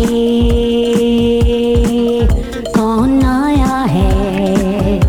Sounds like a subcontinental cover of Michael Jackson's "Don't stop" but it also makes me think of Evelyn "Champagne" King.
verse.